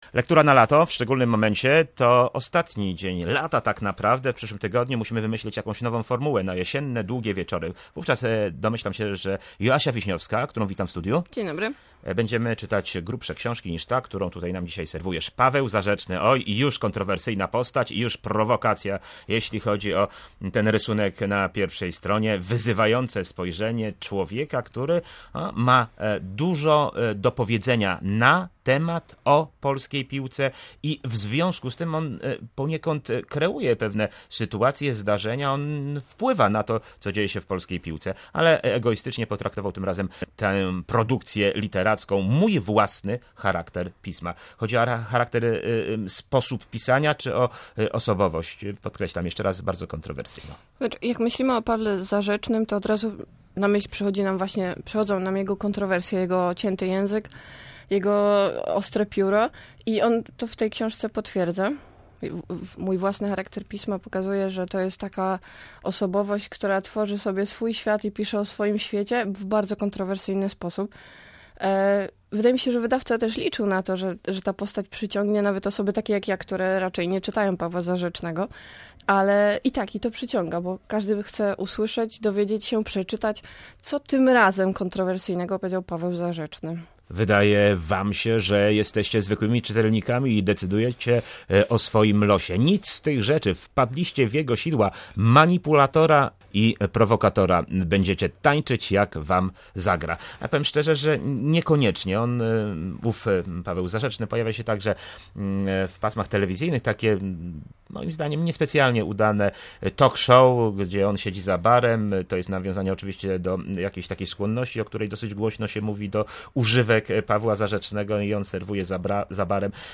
sportowa blogerka
w rozmowie